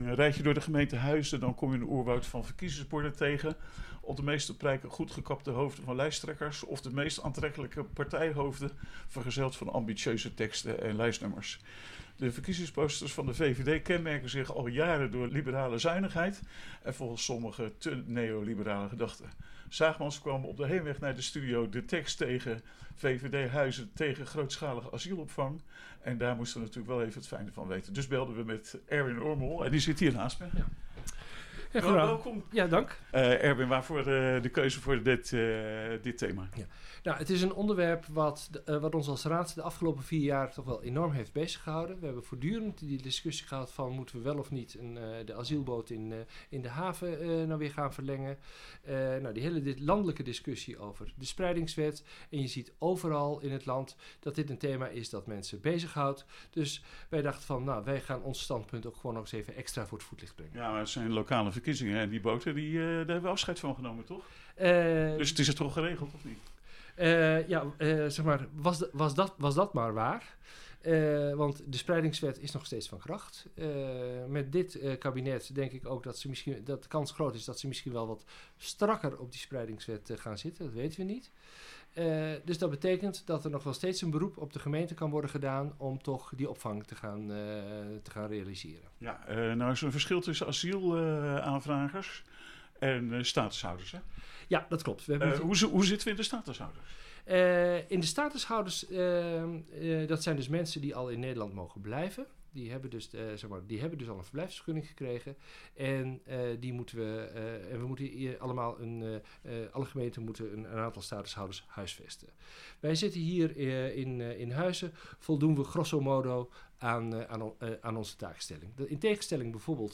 En vandaag is hij in de uitzending.